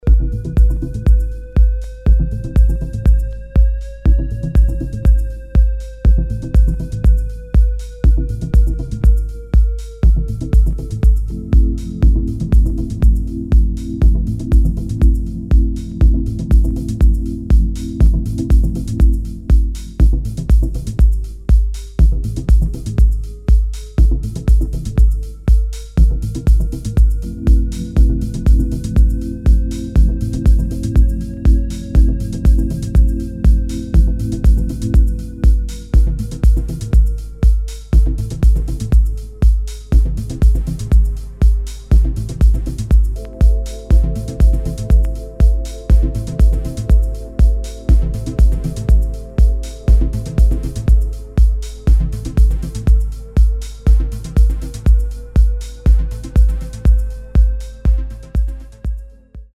[ TECHNO / MINIMAL / ACID / HOUSE ]